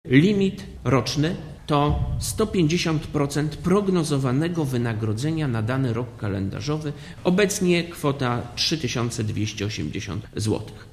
Dla Radia Zet mówi minister Pater (85 KB)